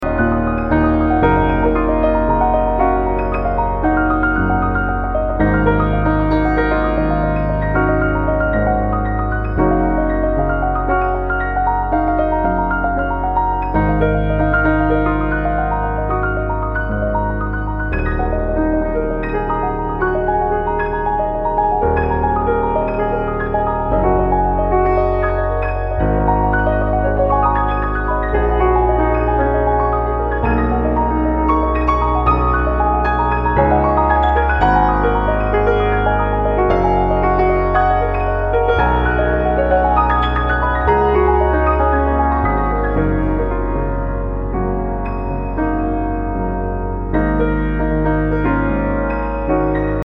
Post Classical >